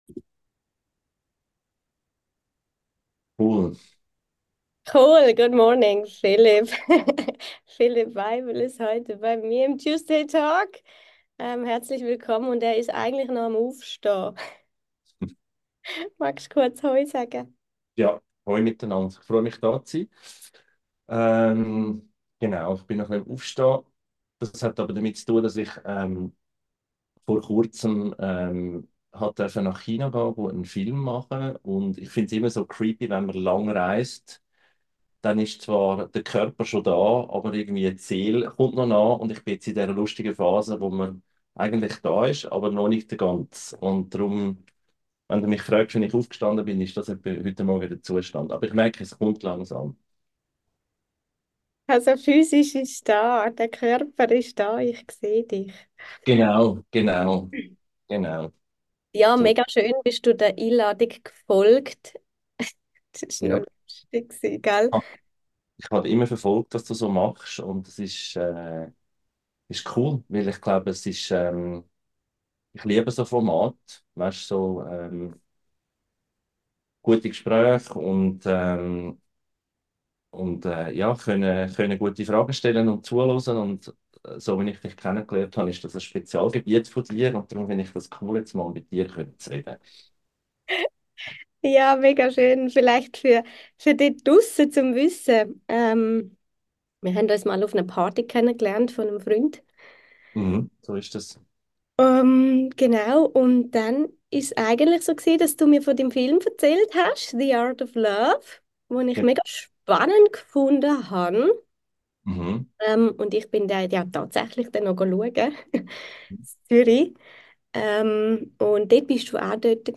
Hier ist alles ECHT - kein Filter, kein Schnitt, kein irgendwas. So wie wir miteinander gesprochen haben, so hörst du hier rein - als ob du live dabei wärst.